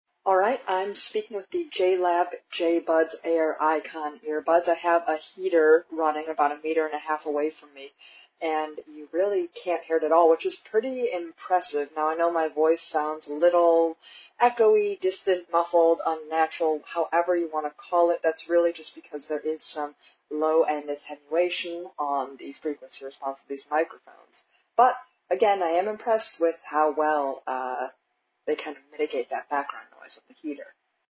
Microphone quality isn’t great
No matter your vocal register, you’re bound to sound slightly “distant” or “echo-y” due to the heavy-handed low-end attenuation.
Microphone quality isn’t great as low-end frequencies are heavily attenuated, leaving lower voices to suffer from a slight echo or “distant” sound. However, I’m impressed by how well the microphones focus on relaying just the immediate sound of one’s voice; during the demo recording below, a heater was running right next to me and is nearly imperceptible.
JLab JBuds Air Icon microphone demo:
JLab-Buds-Air-Icon-microphone-demo.mp3